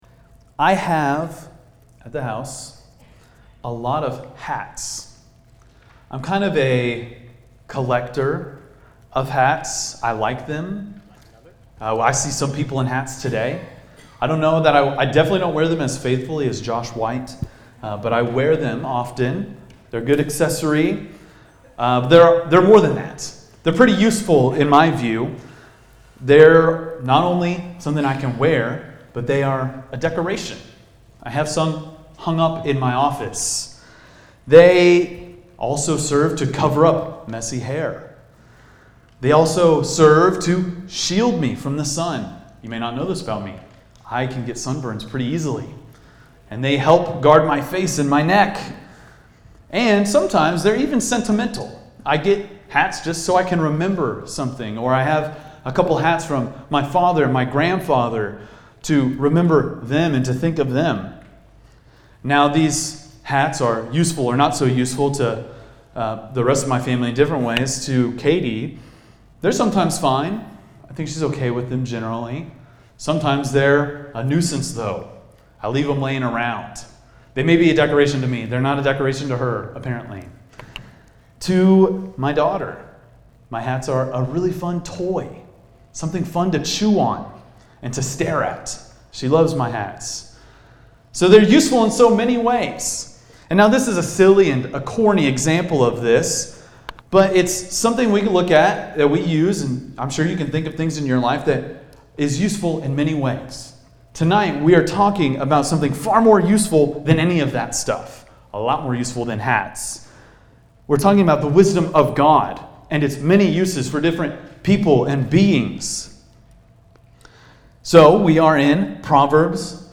preaches through Proverbs 3:13-35.&nbsp